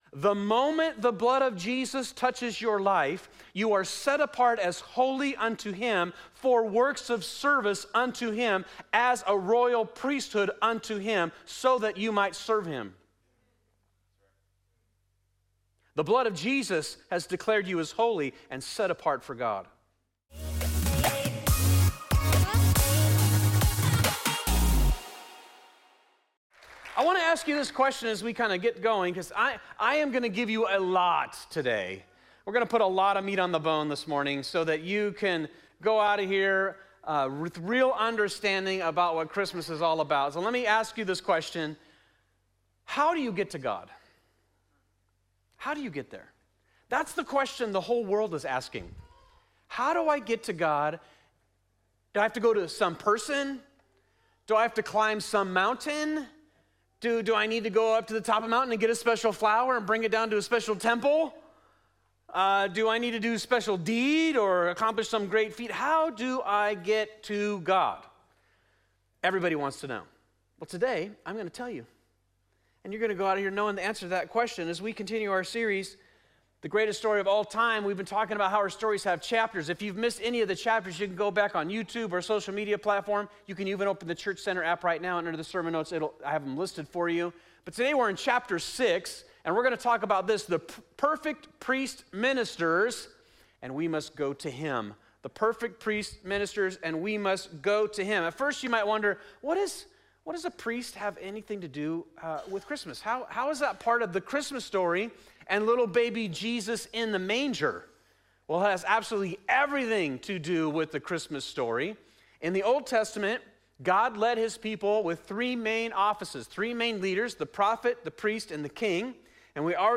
In part 4, we conclude our series with a teaching on how Jesus Christ is our great High Priest.